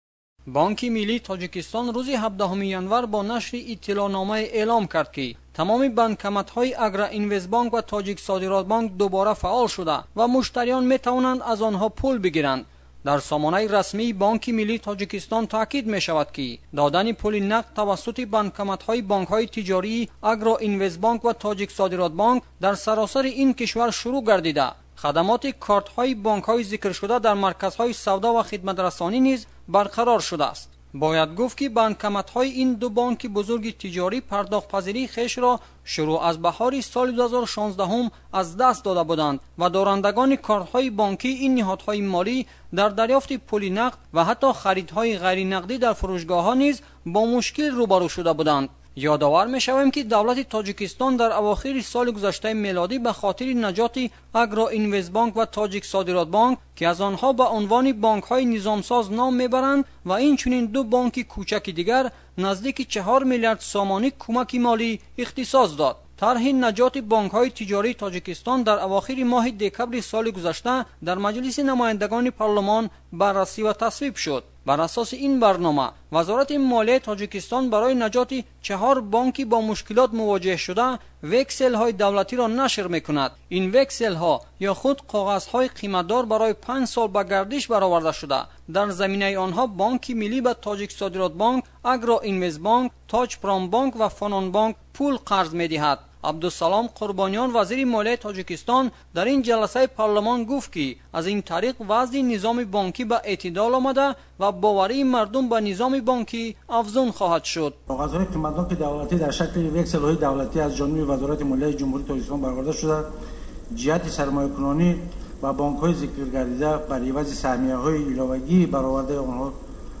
гузориши вижаи